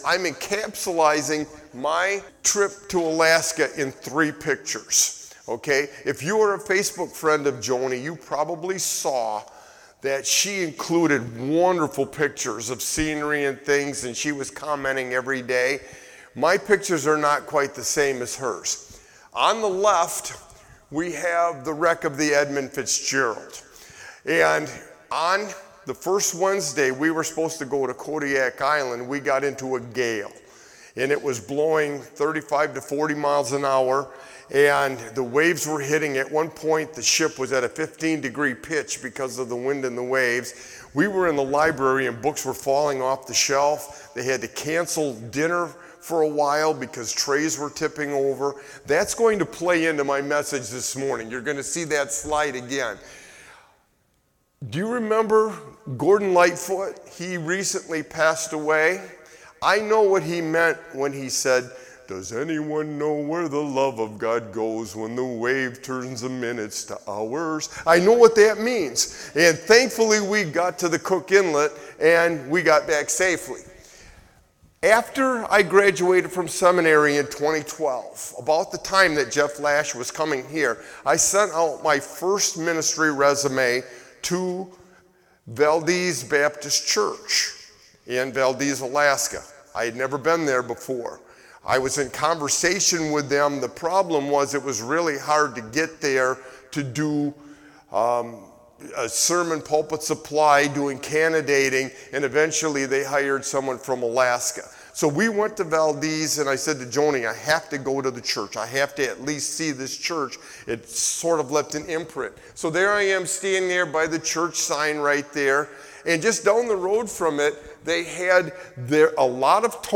Matthew 7:24-27; The final message of the Sermon on the Mount series contains a profound warning to two groups of “everyone” who hear the words of Jesus.